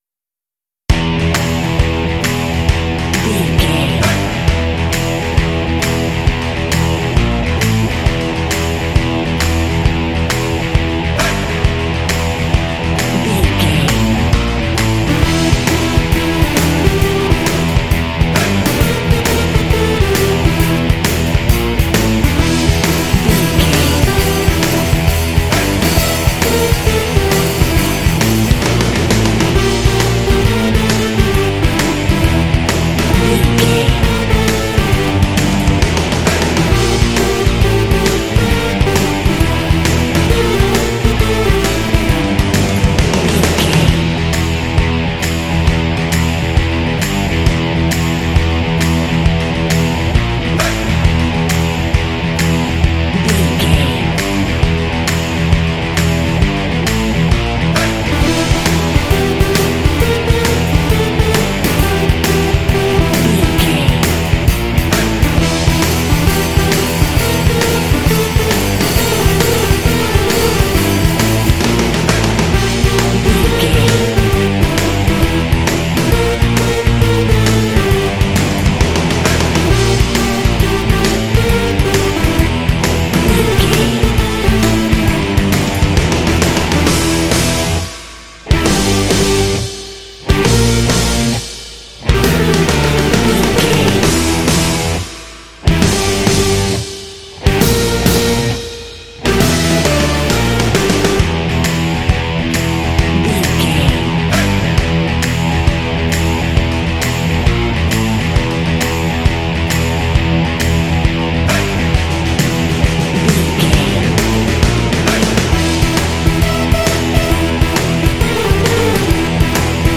Aeolian/Minor
funky
energetic
bouncy
bass guitar
electric guitar
drums
synthesiser
alternative rock